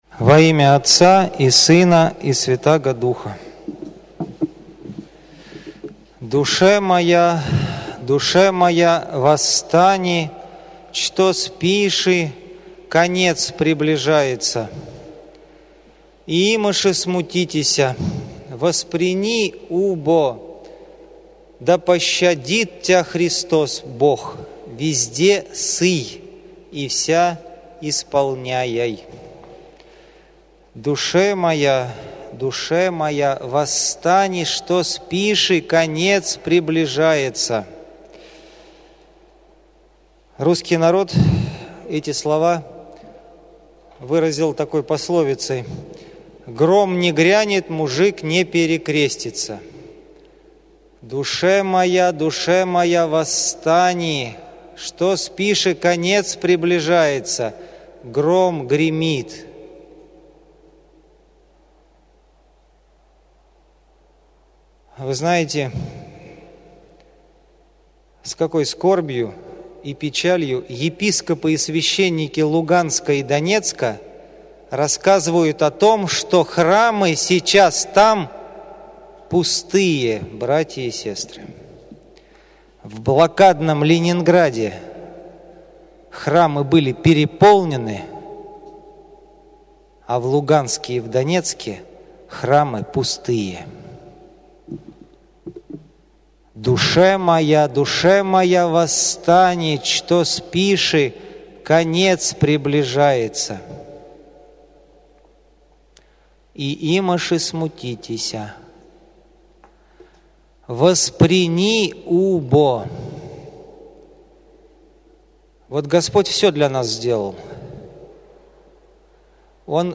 Чтение канона Адрея Критского и проповедь в четверг первой недели Великого поста
в Аудио / Проповеди by 27.02.2015